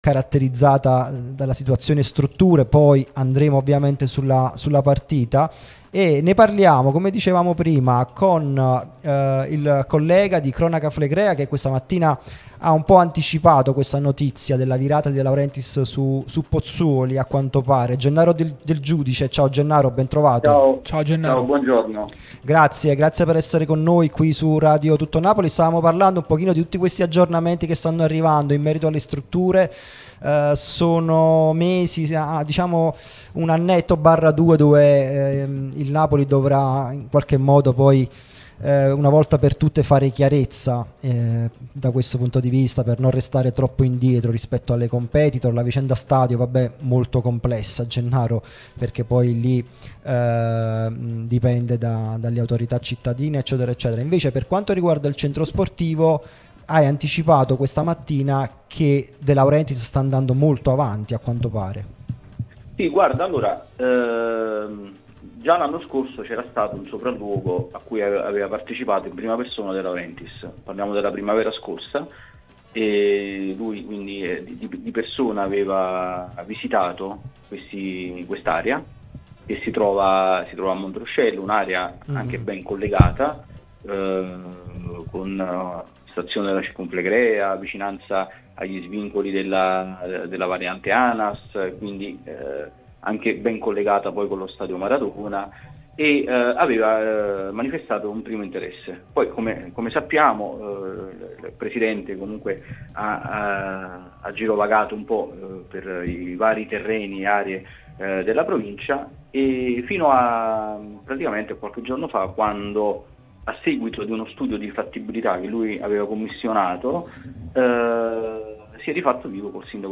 è intervenuto nel corso di "Napoli Talk" sulla nostra Radio Tutto Napoli